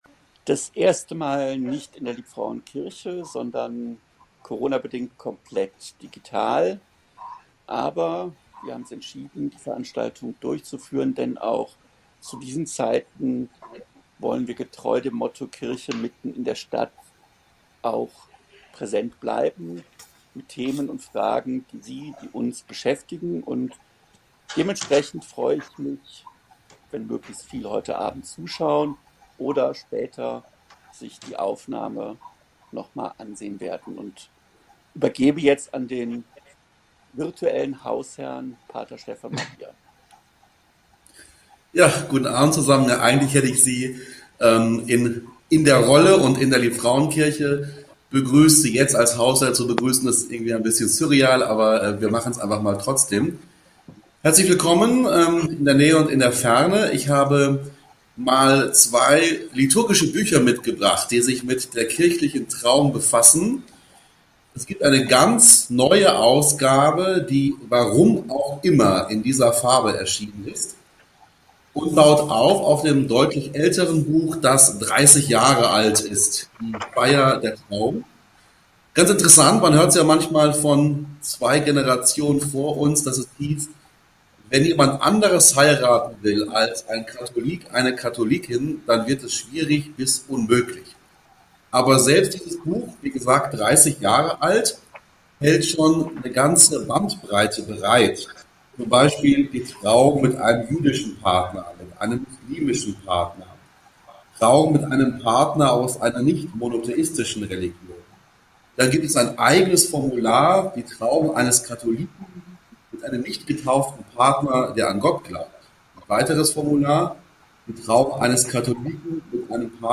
Diskussionsveranstaltung des Liebfrauenforums und der Katholischen Erwachsenenbildung vom 09.02.2021